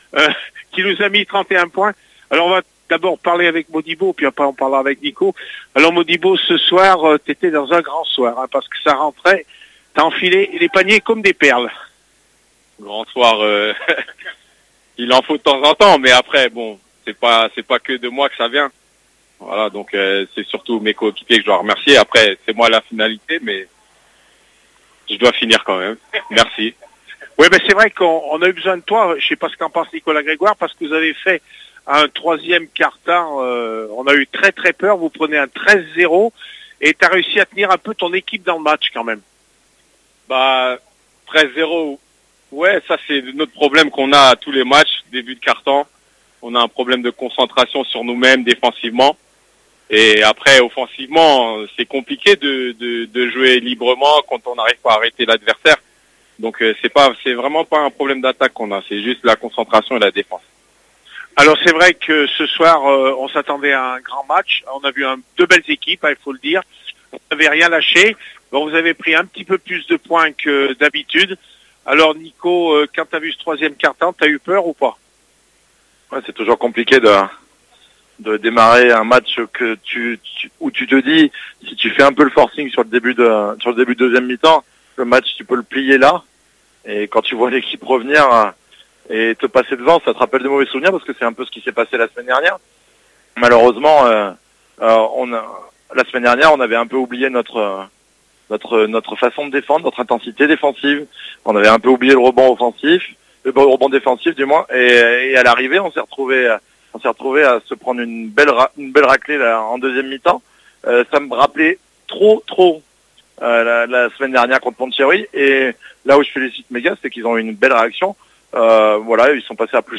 ASMB LE PUY BASKET-GOLFJUAN VALLAURIS REACTIONS D’APRES MATCH 281115